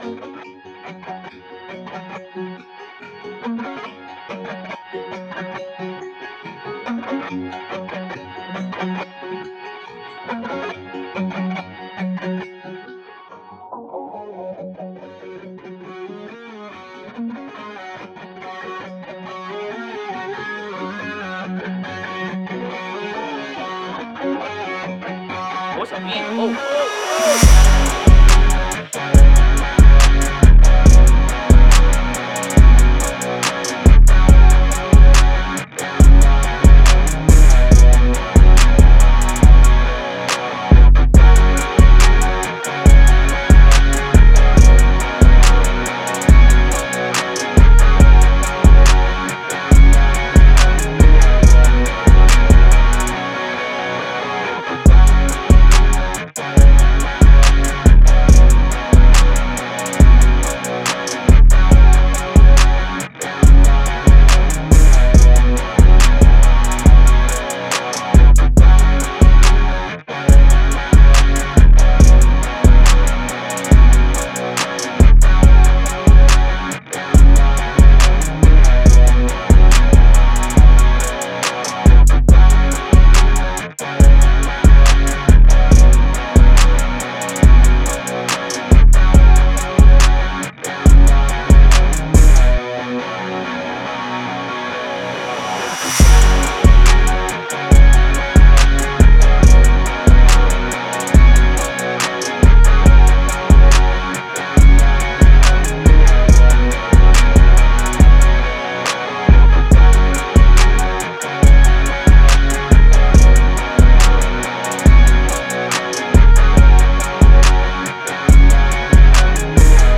Time – (2:18)　bpm.127